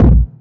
GS Phat Kicks 021.wav